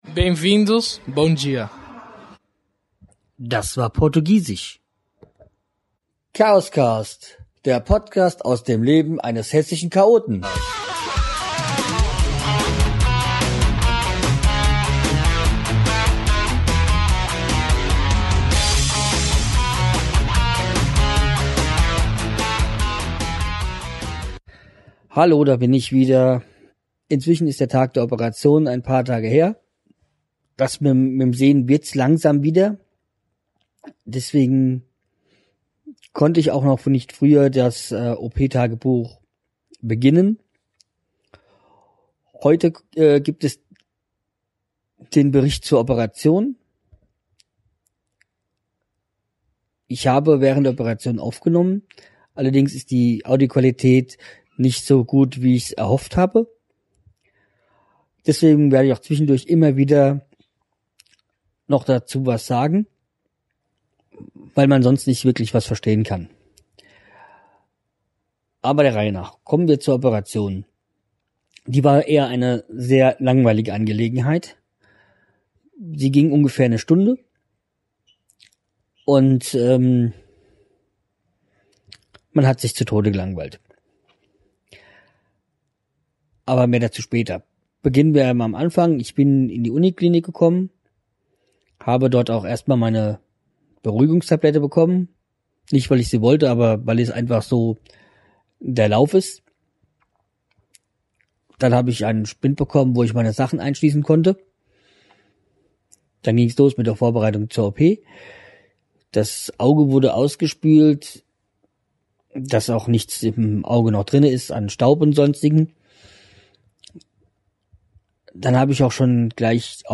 Hier kommen nun die Aufnahmen bzw. der Bericht über die Augen OP. Sorry! Leider ist die Tonqualität im Mittelabschnitt leider nicht so gut.